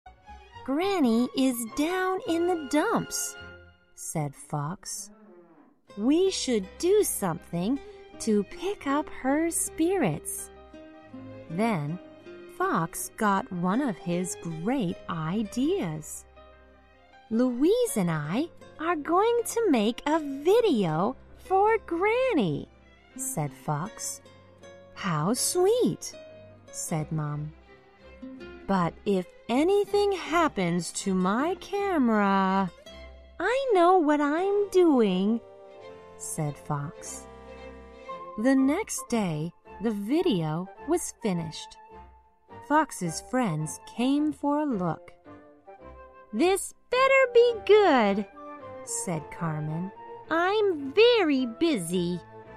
在线英语听力室小狐外传 第64期:奶奶情绪的听力文件下载,《小狐外传》是双语有声读物下面的子栏目，非常适合英语学习爱好者进行细心品读。故事内容讲述了一个小男生在学校、家庭里的各种角色转换以及生活中的趣事。